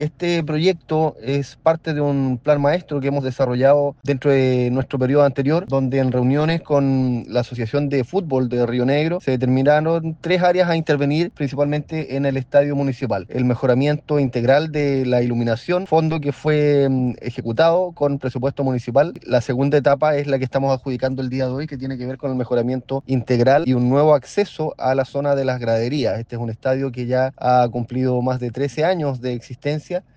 Un proyecto socializado con la Asociación de Fútbol de Río Negro, cuyas obras se desarrollarán mientras se ejecuta el campeonato, permitiendo que los futbolistas sigan con sus actividades y partidos en el estadio, sin la asistencia de público. Así lo explicó, el alcalde de la comuna, Sebastián Cruzat.